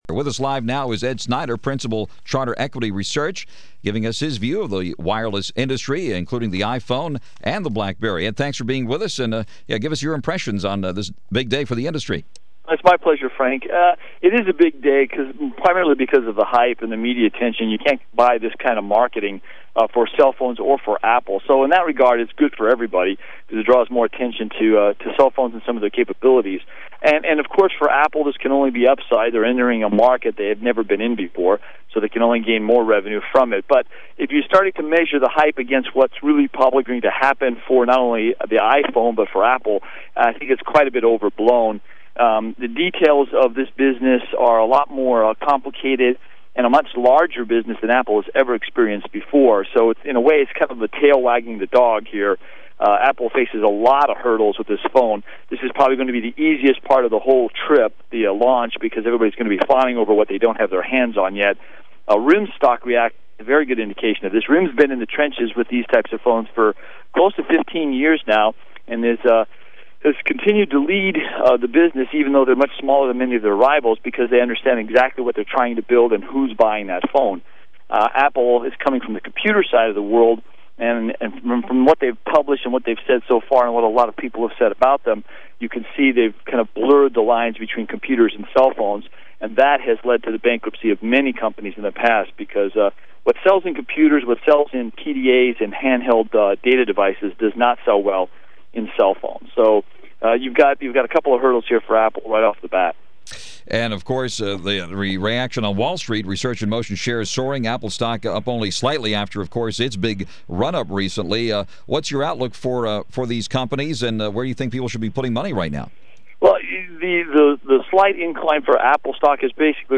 wireless industry press coverage KNX Business Hour, Los Angeles Radio Interview, June 29, 2007